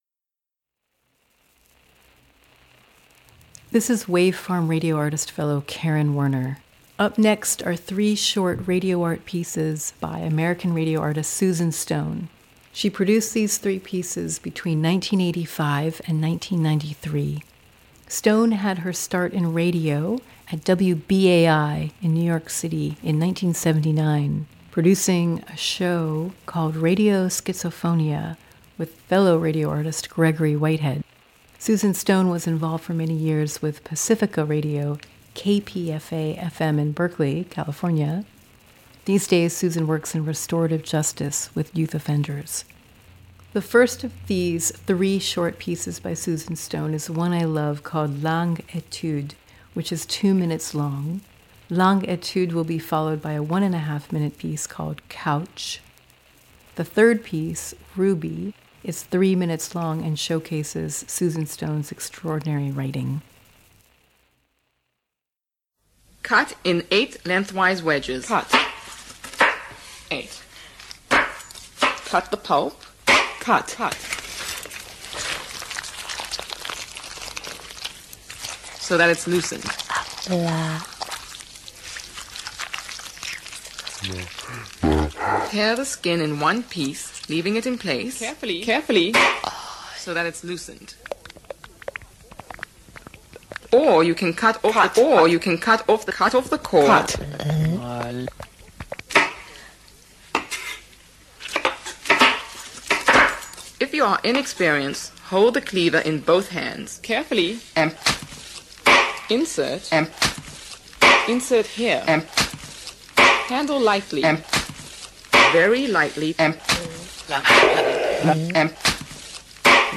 The piece conveys, among many things, the literal and metaphoric meanings of cutting tape.
The piece showcases extraordinary writing, character development, acting, and editing.